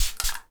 spray_bottle_07.wav